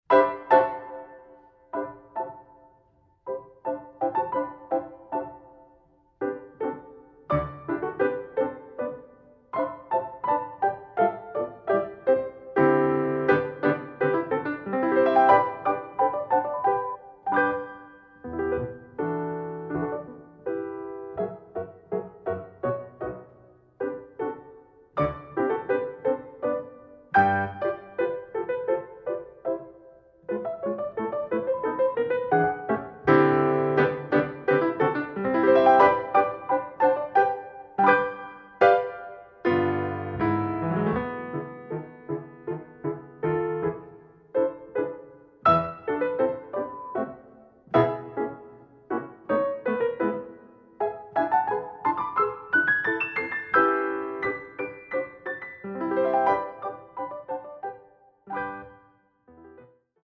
Voix Haute